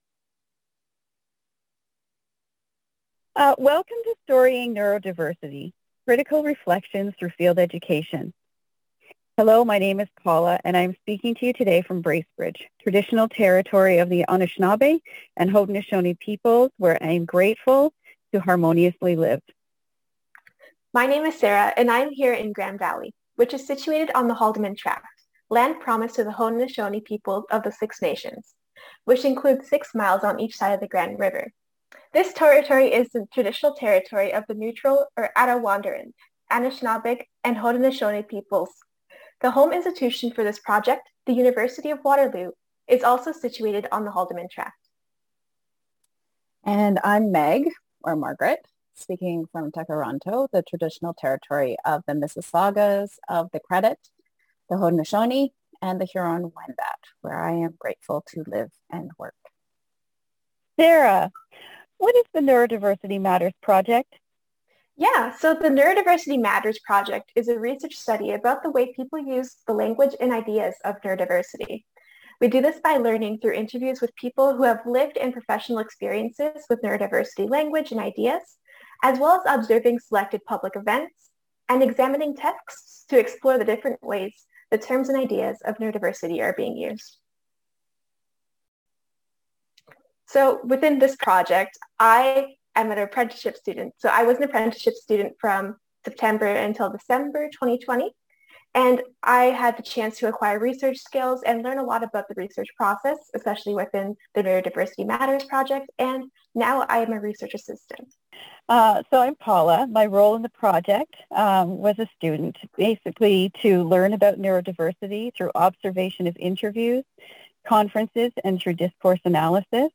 Our presentation is a critical reflective podcast discussion between two students (MSW and BA candidates) and a faculty member/ field instructor.